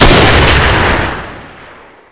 sol_reklam_link sag_reklam_link Warrock Oyun Dosyalar� Ana Sayfa > Sound > Weapons > Ssg Dosya Ad� Boyutu Son D�zenleme ..
WR_fire.wav